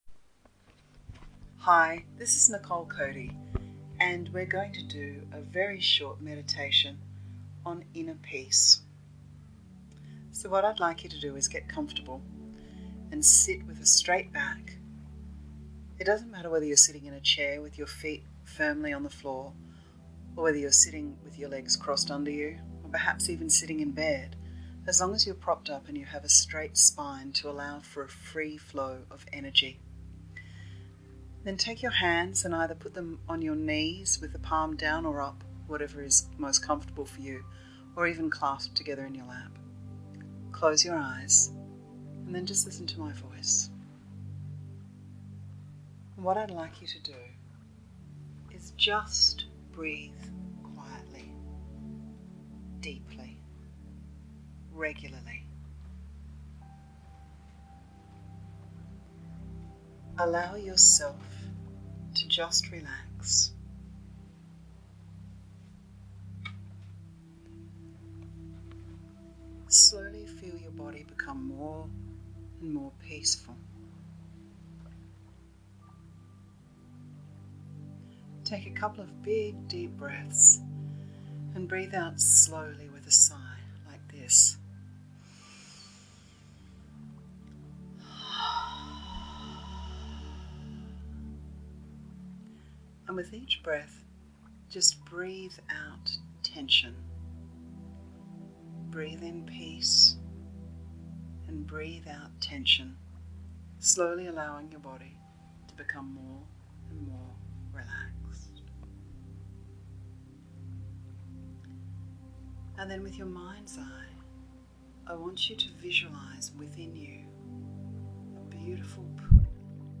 Here is a short guided meditation to nurture your connection to inner peace: